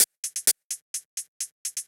Index of /musicradar/ultimate-hihat-samples/128bpm
UHH_ElectroHatB_128-02.wav